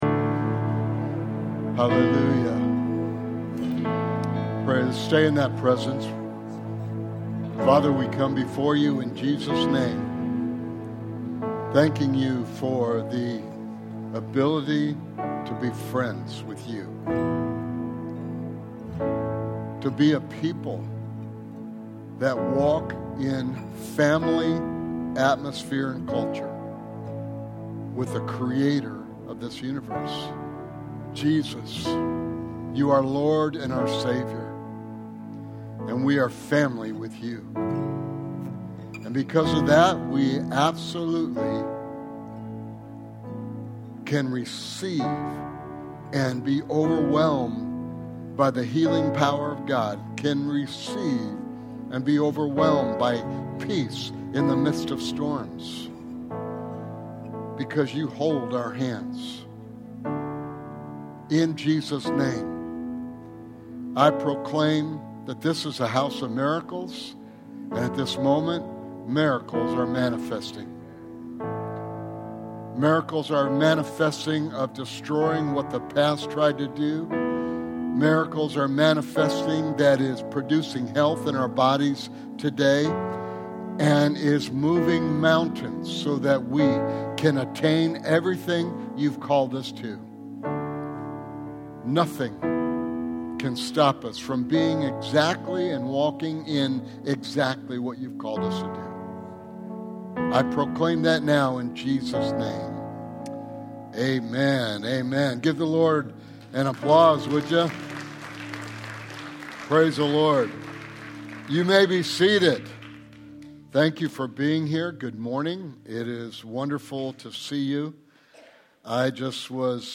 Sermon Series: Tune In and Hear God